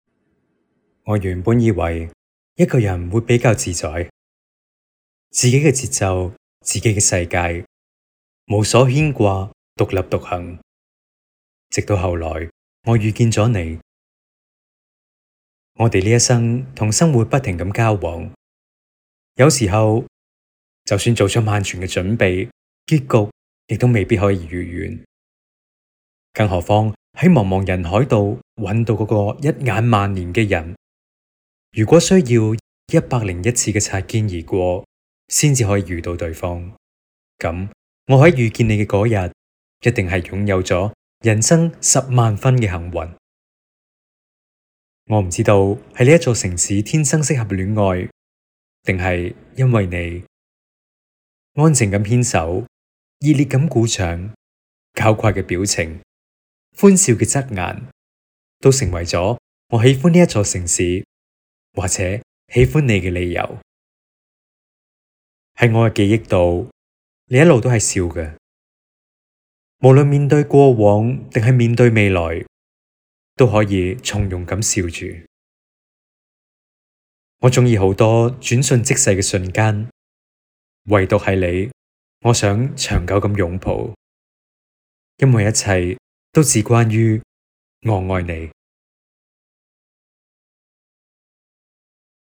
当前位置：样音试听  > 方言试听 > 粤语 > 粤语男4号
• 旁白
• 低沉